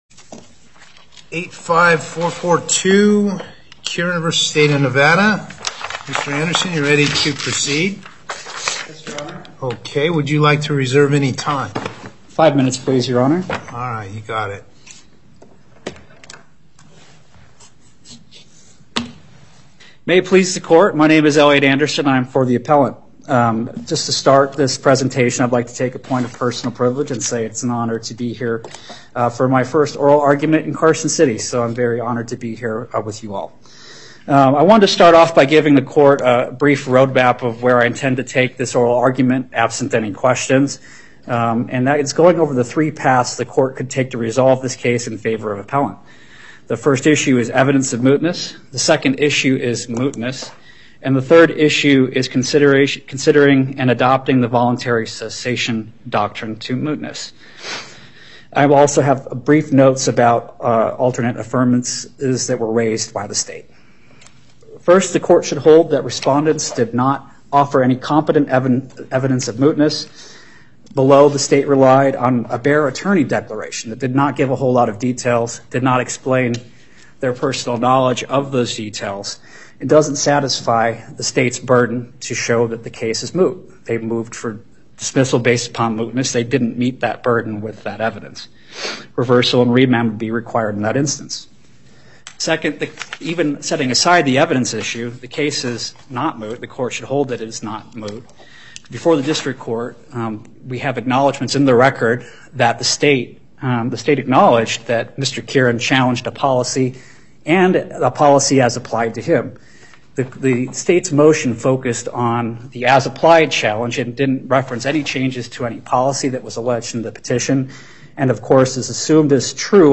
Before Panel A25, Justice Parraguirre presiding Appearances
on behalf of Respondent